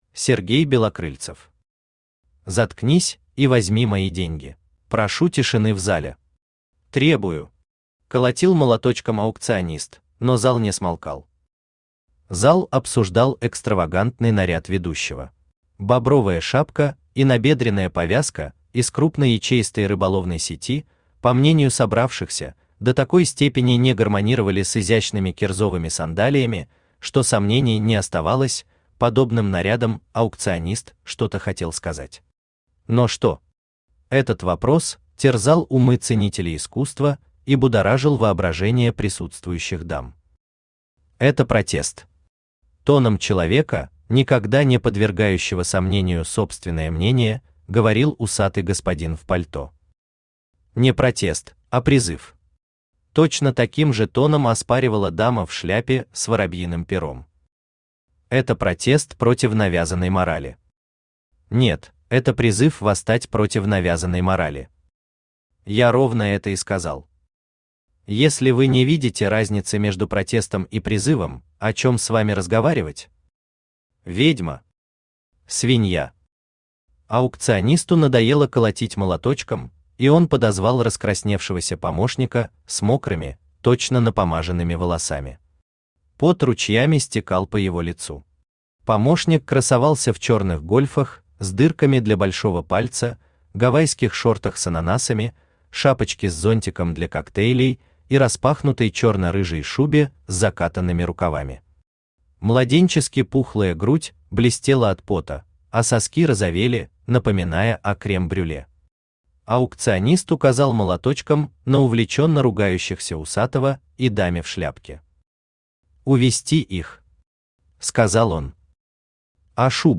Аудиокнига Заткнись и возьми мои деньги!
Автор Сергей Валерьевич Белокрыльцев Читает аудиокнигу Авточтец ЛитРес.